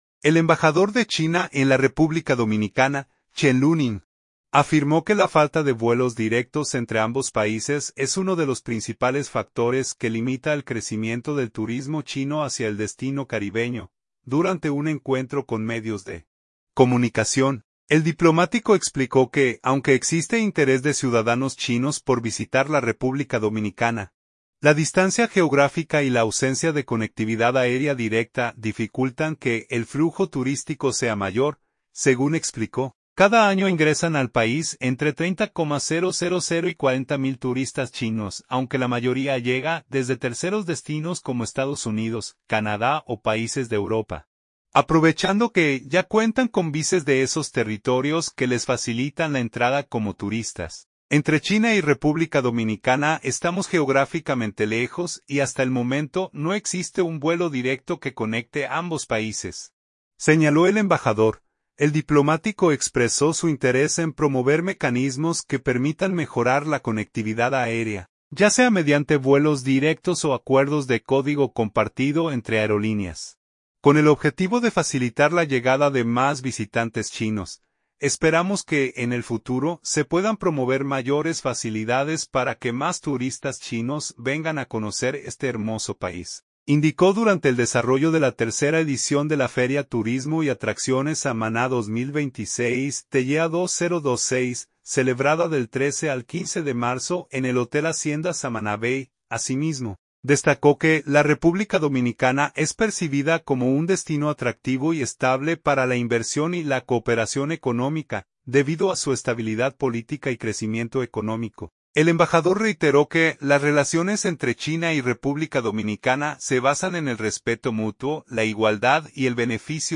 Durante un encuentro con medios de comunicación, el diplomático explicó que, aunque existe interés de ciudadanos chinos por visitar la República Dominicana, la distancia geográfica y la ausencia de conectividad aérea directa dificultan que el flujo turístico sea mayor.
El embajador de China en el país, Chen Luning, ofrece declaraciones.